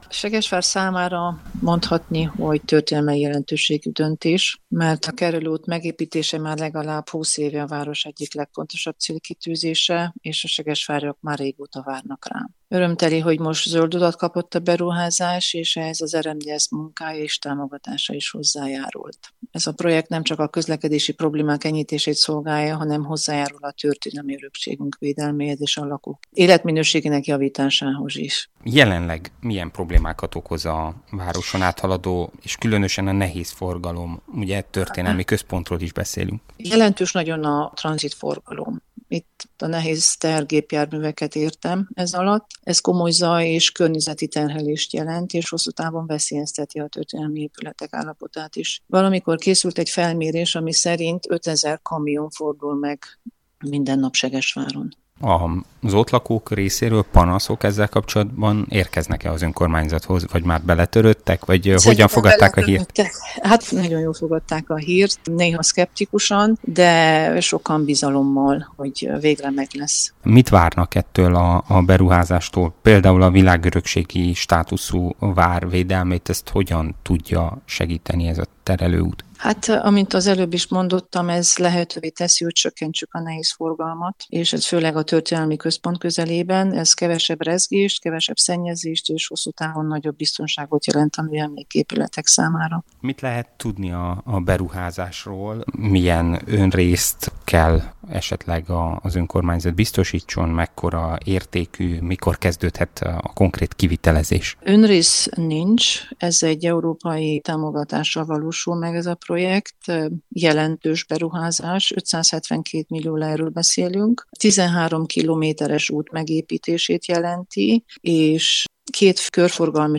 Hogy milyen változást hozhat a segesváriak életében és a turizmusban a terelőút, arról Kreuzer Erzsébetet, Segesvár alpolgármesterét kérdeztük.